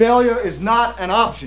Although Tom Hanks, as Jim Lovell, plays the main role, my favourite qoutation comes from Gene Kranz (Ed Harris):